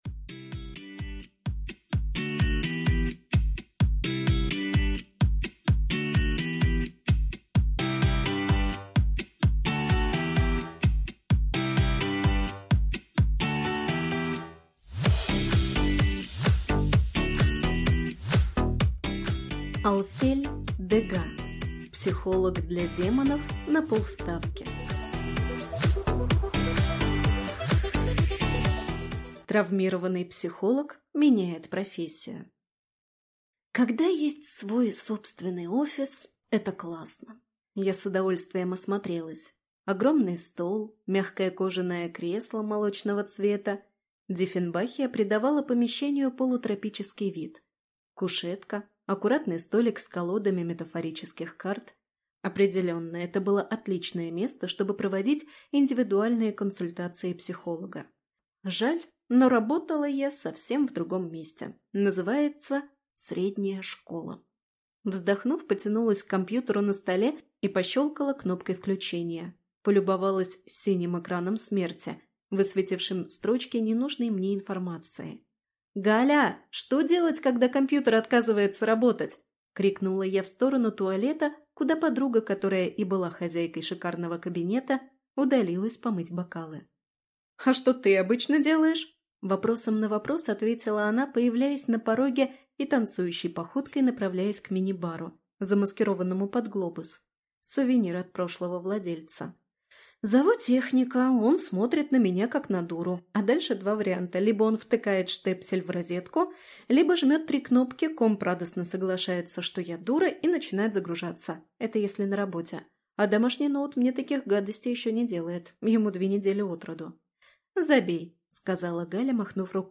Аудиокнига Психолог для демонов на полставки | Библиотека аудиокниг